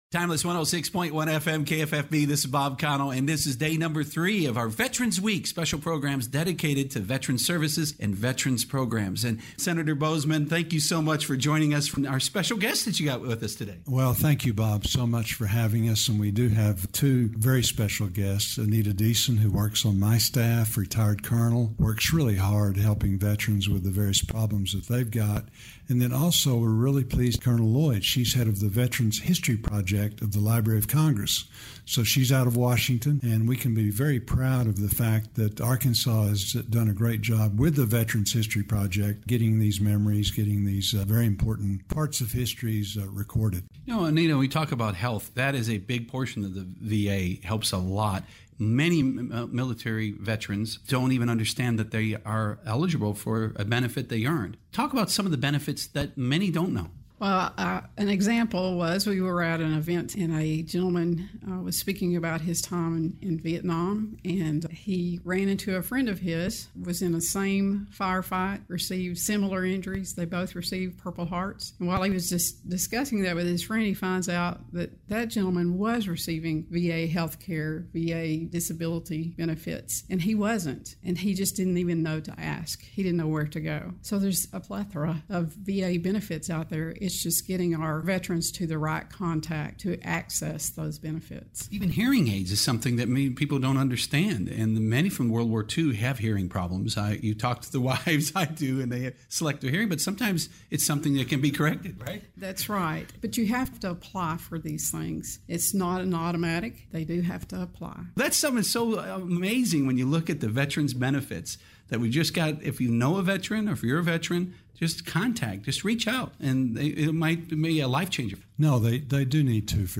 U.S. Senator John Boozman (R-AR) on Timeless 106.1 KFFB’s Open Mic Day #3 Wednesday, November 13, 2019, for Veterans Week, November 11th-15th. The interview program